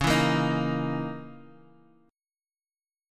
Dbm6add9 chord